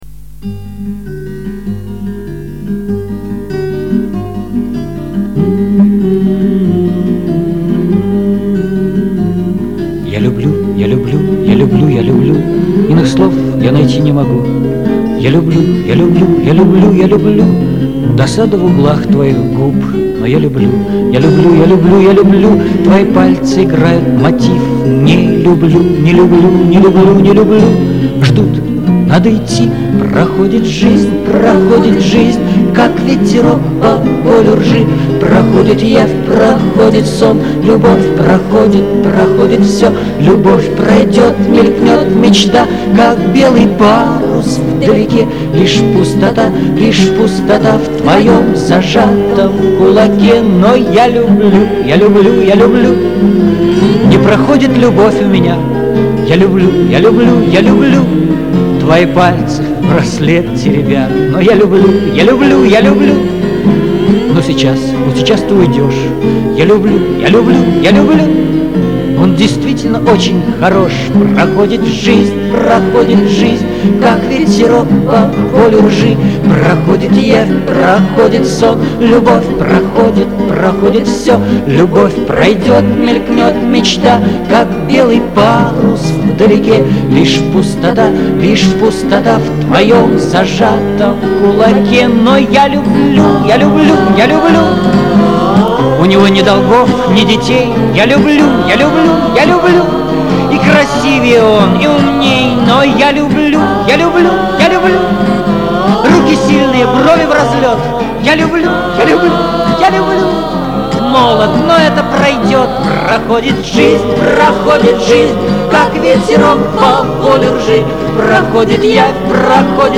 Известной, эта бардовская  песня
Душевно и немного грустно...